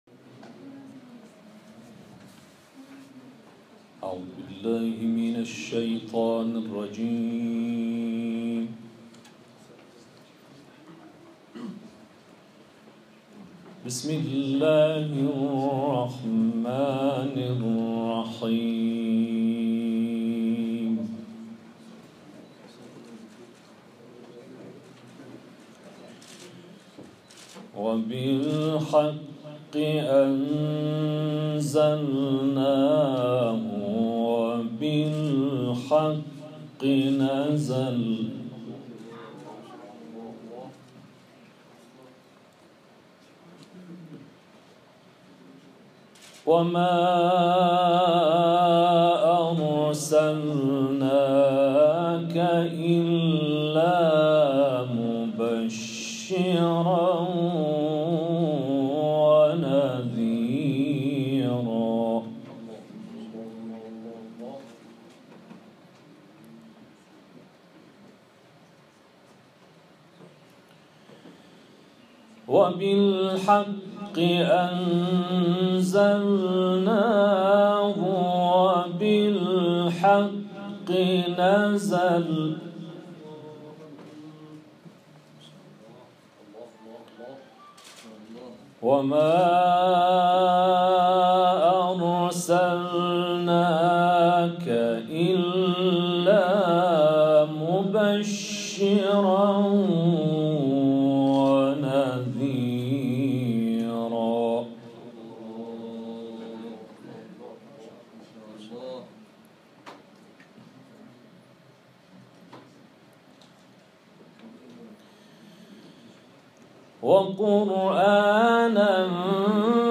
تلاوت آیات 105 تا 111 سوره اسراء